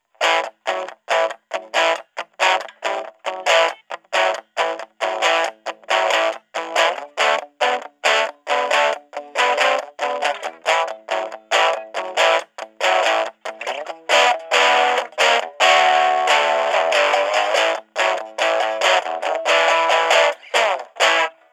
Before we get started, let me say that yes, the amp was recorded using an extension cable.
Bridge Rockin’
Guild-TweedyBird-X175-Bridge.wav